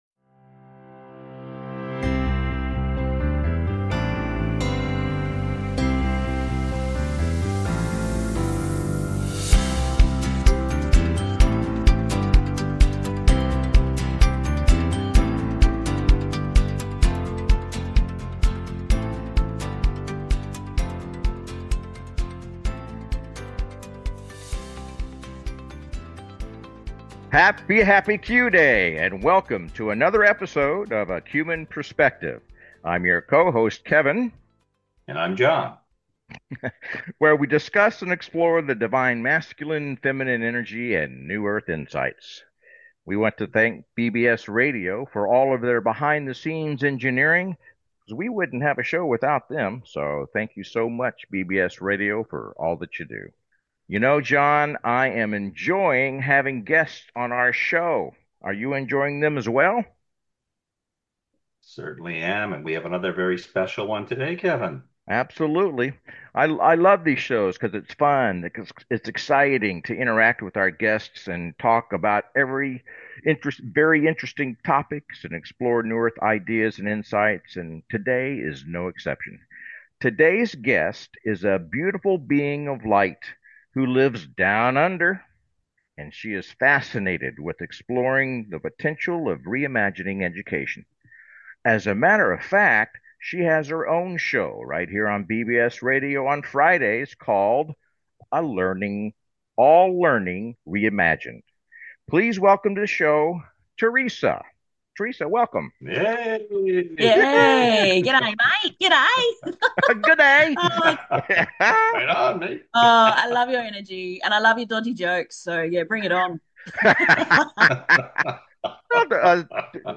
Talk Show Episode
The show is structured to welcome call-ins and frequently features special guests, offering a diverse range of perspectives.
The show is not just informative but also entertaining, with humor, jokes, and a whole lot of fun being integral parts of the experience.